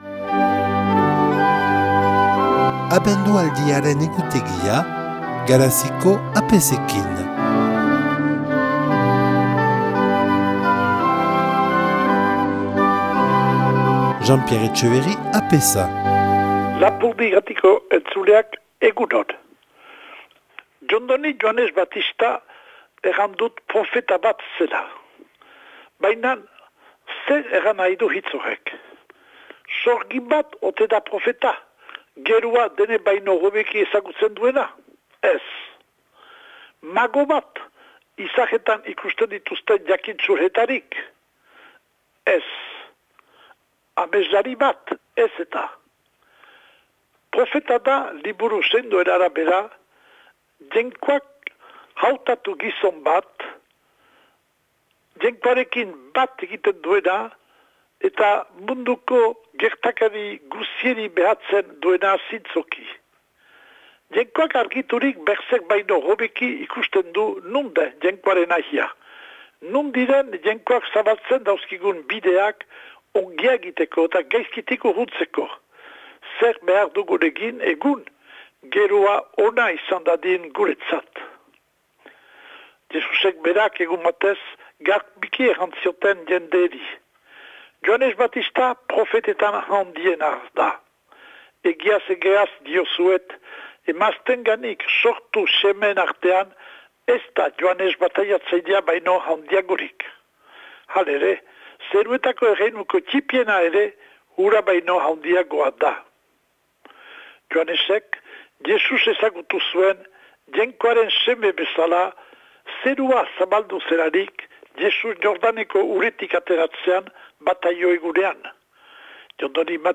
Les prêtres de la Paroisse ont un billet quotidien sur Radio Lapurdi en Basque. Diffusion du message de l'Avent, chaque jour à 7h25, 12h25, 15h10 et 20h25